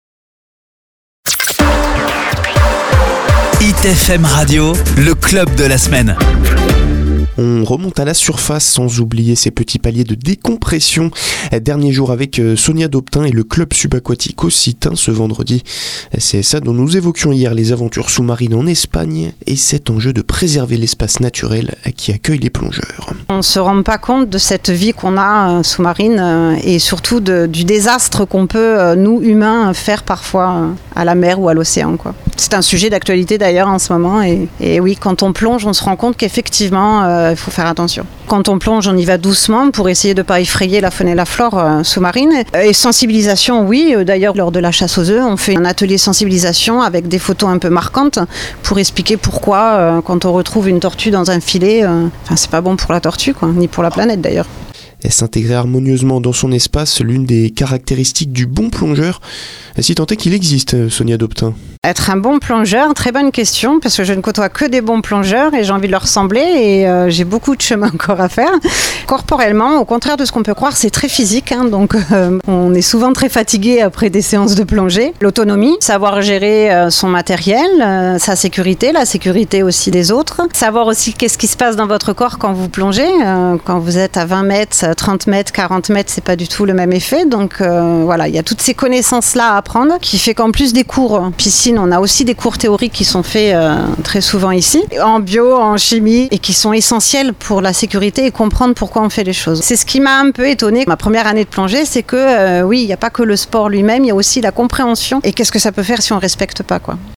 LE CSA PASSE A LA RADIO SUR HIT FM